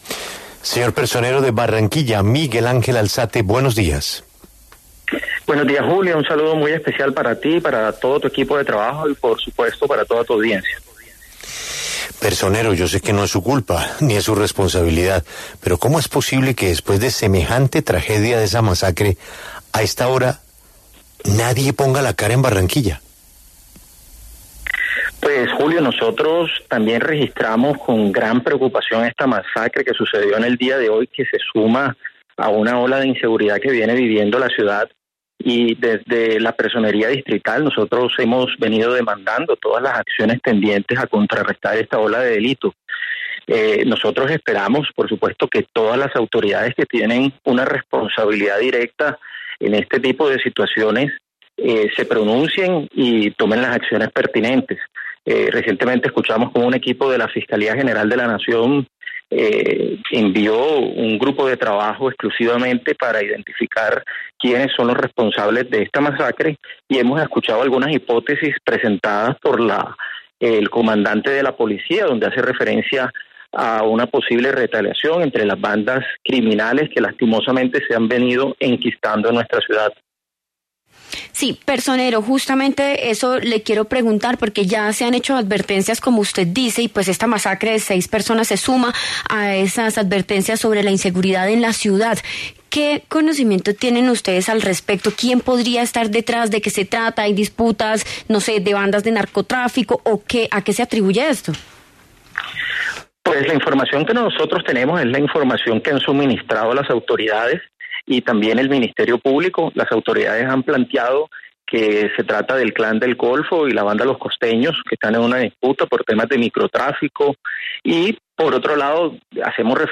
En diálogo con La W, el personero Miguel Ángel Alzate se refirió a la masacre registrada la madrugada de este lunes en la capital del Atlántico.